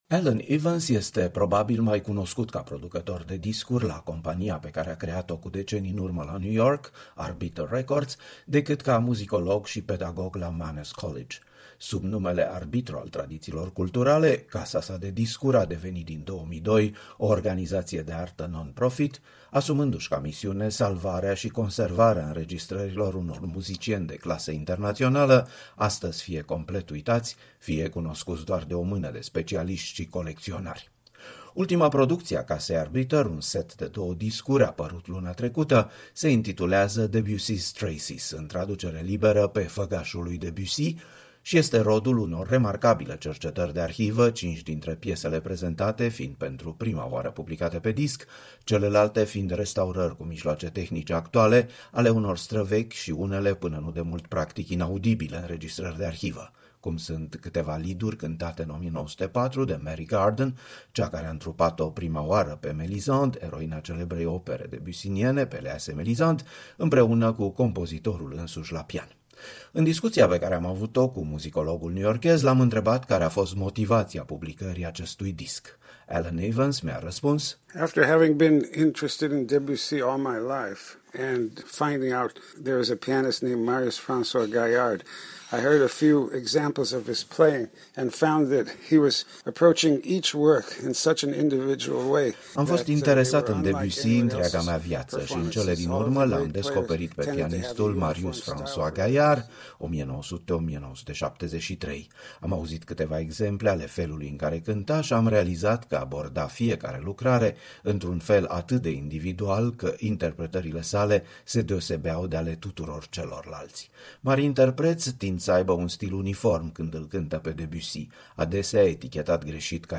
[Extras muzical: Debussy, Estampes, Arbiter CD 166]